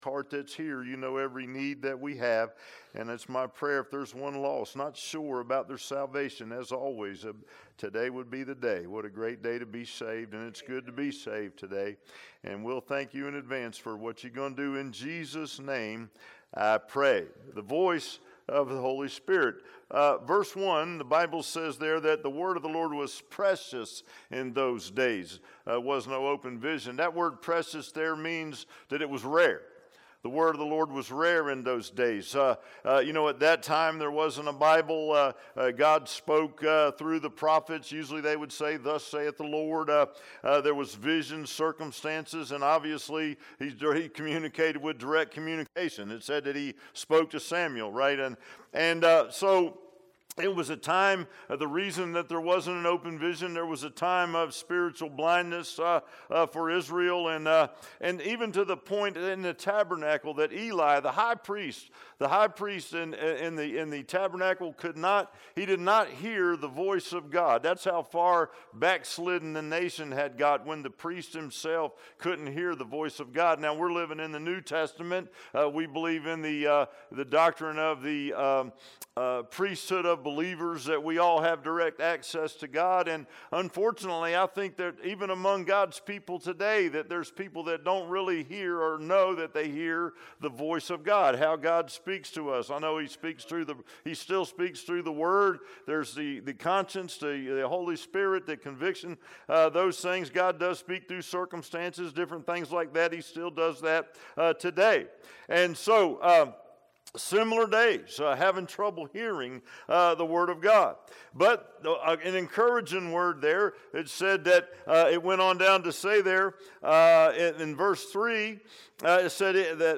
I Samuel 3:1-10 Service Type: Sunday AM « Power In Your Christian Life